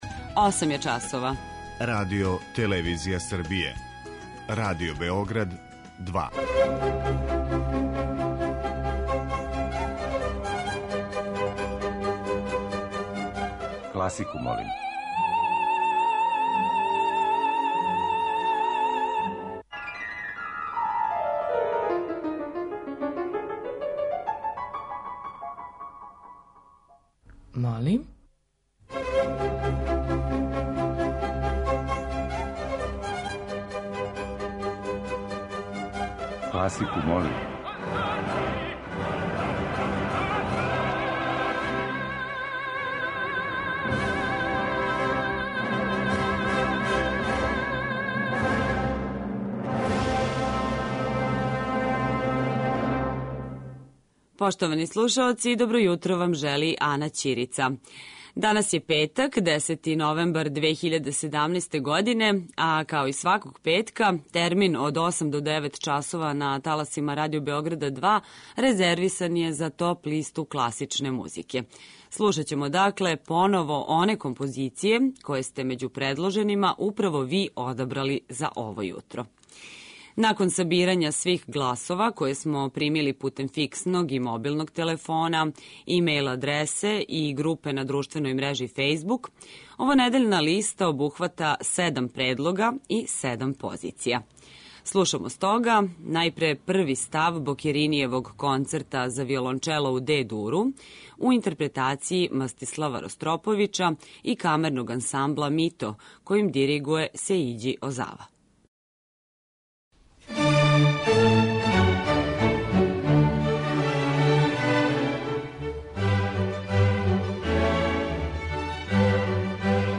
Недељна топ-листа класичне музике Радио Београда 2